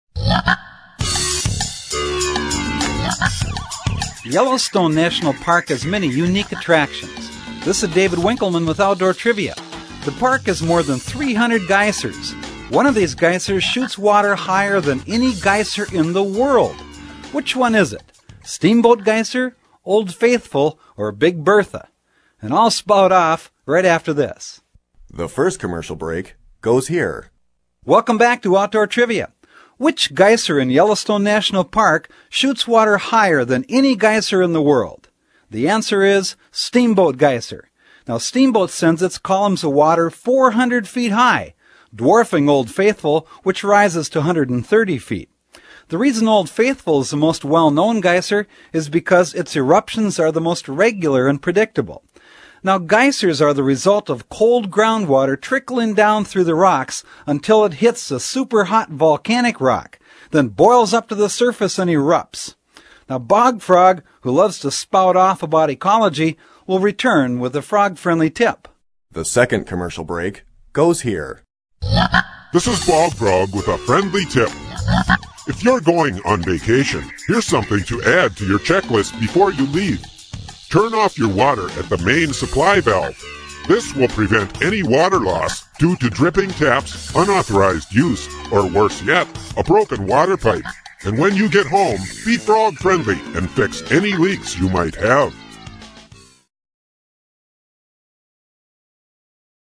In fact, the question and answer trivia format of this program remains for many people, a most enjoyable, yet practical method of learning.
Bog Frog's voice is distinctive and memorable, while his messages remain positive and practical, giving consumers a meaningful symbol to remember.